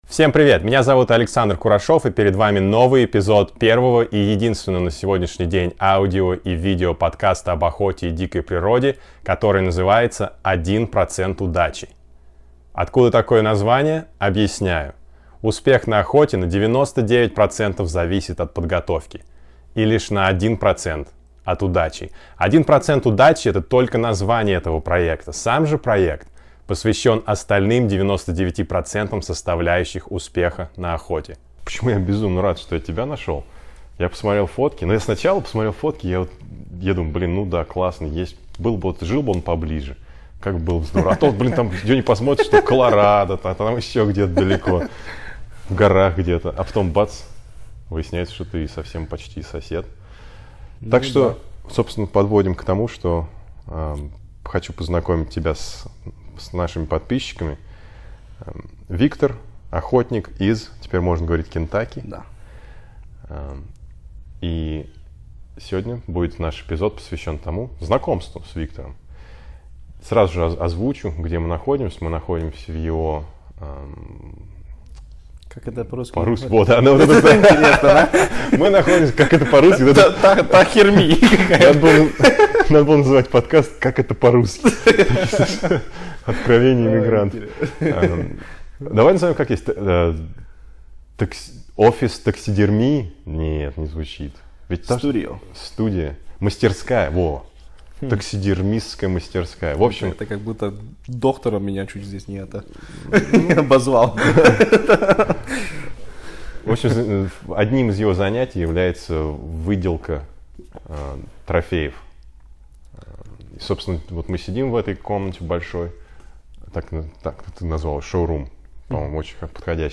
Пообщались с нем о его работе, о его трофеях и о планах на предстоящих охотничий сезон.